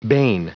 Prononciation du mot bane en anglais (fichier audio)
Prononciation du mot : bane